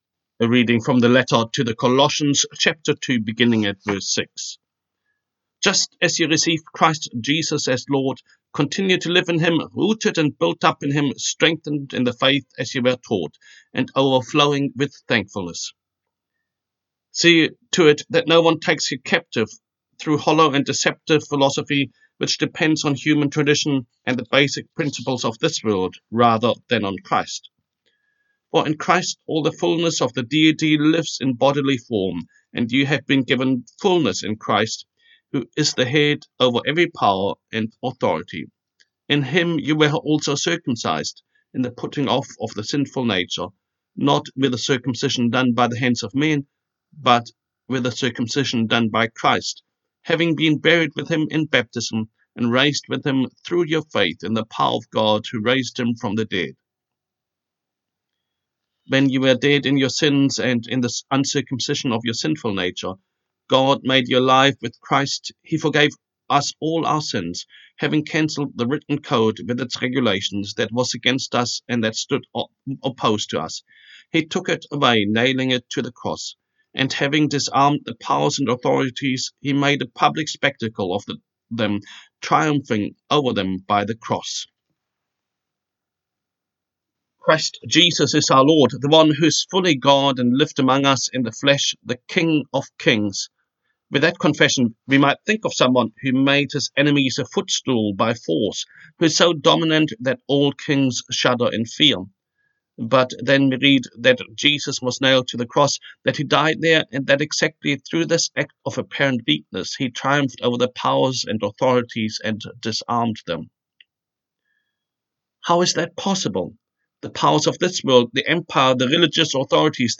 Evensong